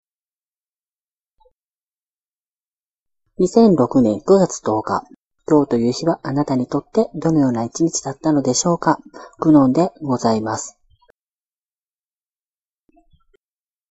ちょっと声がこもり気味だったため、以前とは異なる加工法を行ってみたテスト。 第８ｉ回：内部改修中 ゲームの話はしていないので今回は「第９回」ではなく「第８ｉ回」なので、御注意を。